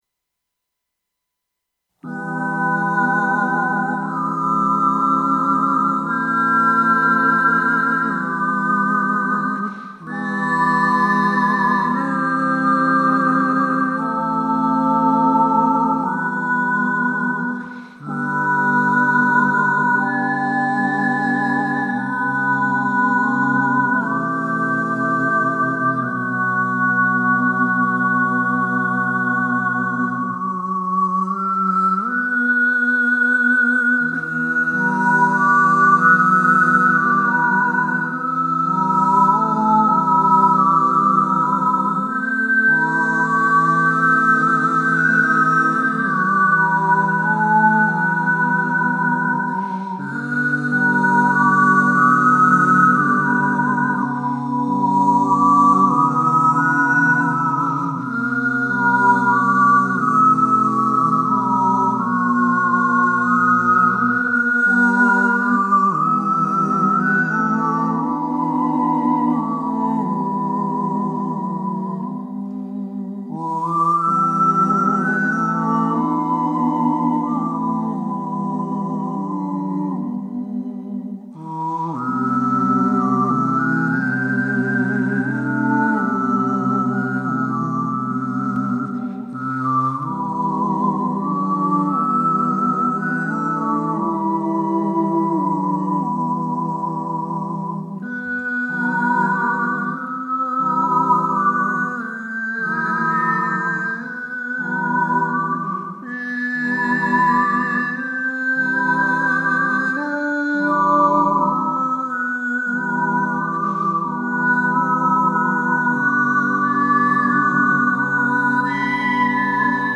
Chaconne – music for ensemble of three polyphonic overtone singers